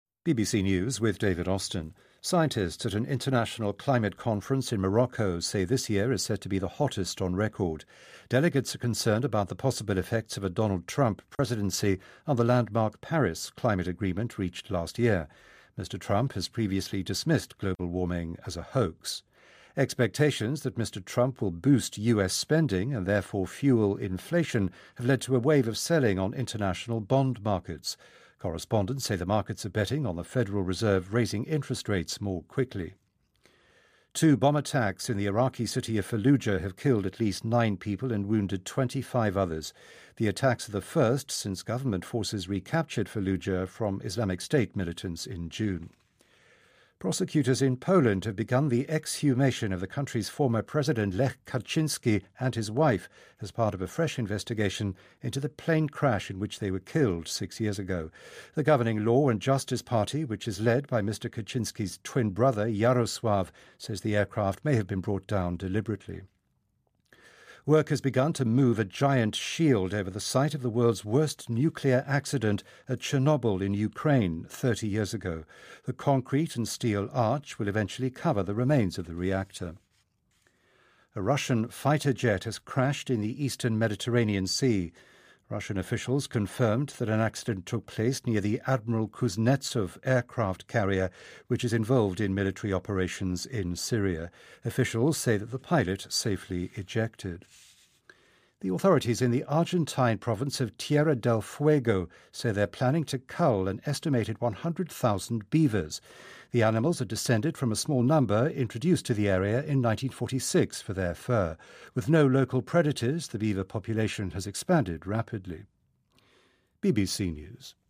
日期:2016-11-16来源:BBC新闻听力 编辑:给力英语BBC频道